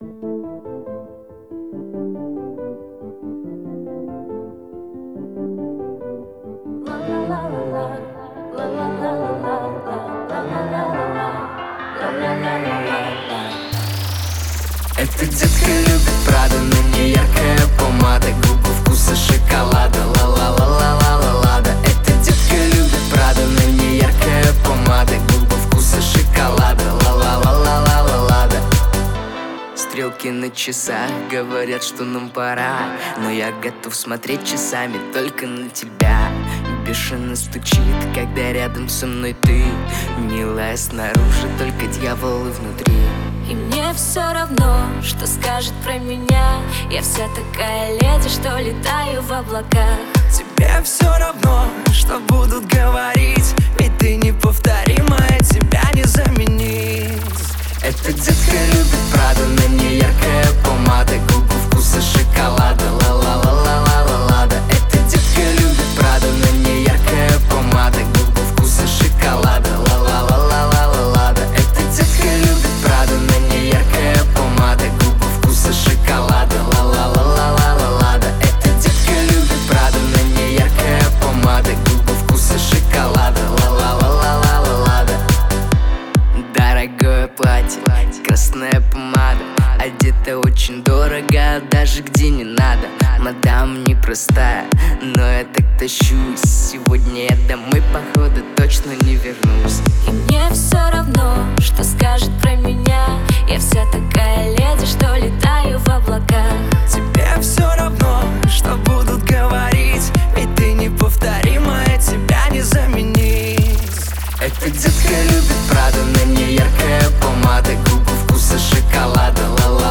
• Категории: Музыка 2020, Поп